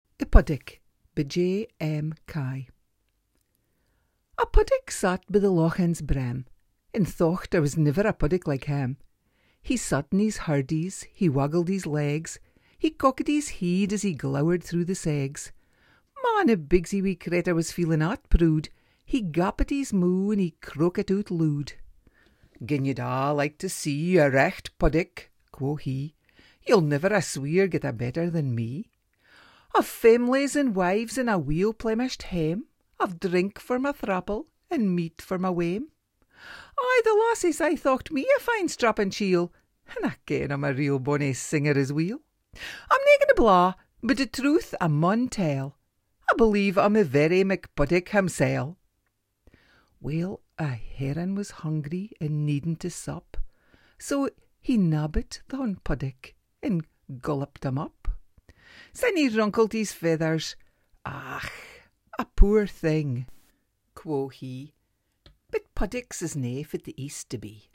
Click here to listen to a reading of the poem